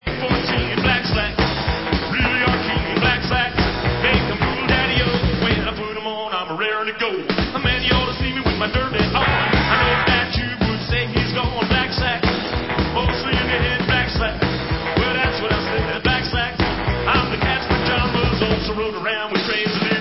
(live)
sledovat novinky v oddělení Rock & Roll